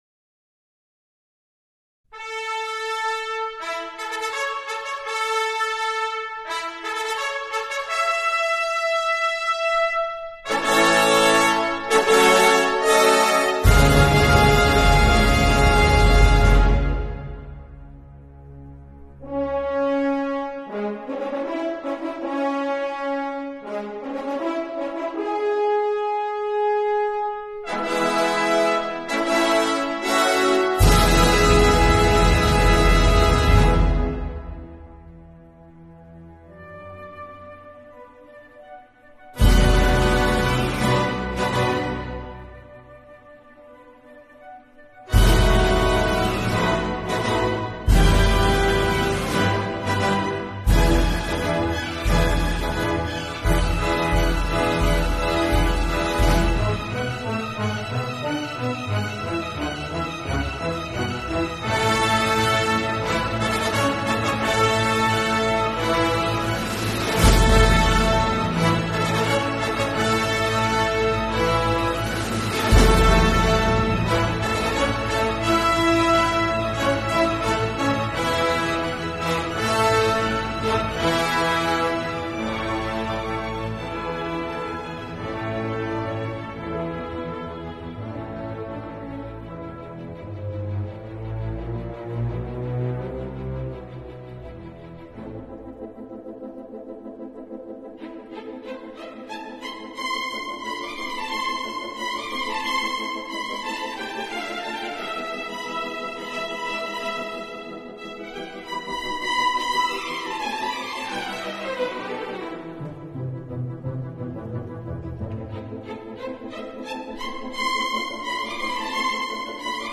This week we will enjoy a programme of “Sundowner” music. Easy listening for the end of the day.